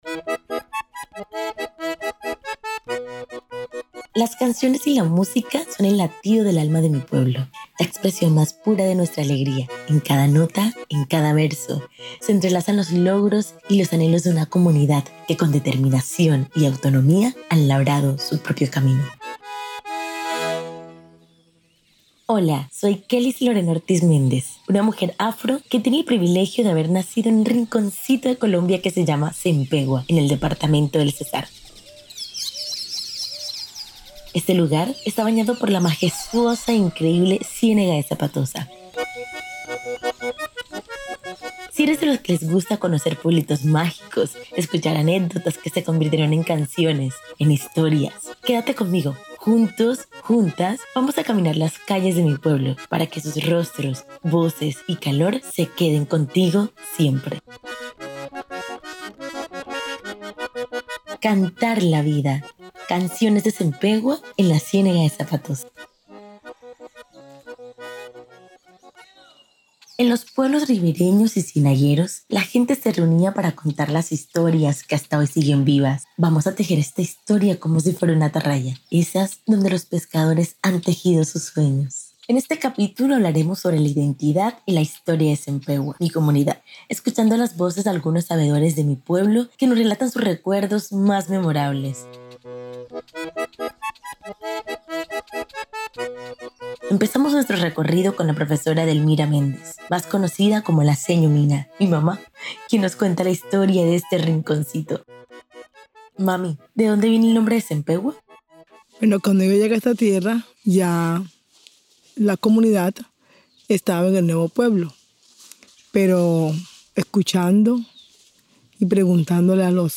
En 1971, las inundaciones forzaron a sus habitantes a reconstruir su vida en otro lugar. Ahora, sus voces reviven el dolor y la lucha por preservar su identidad.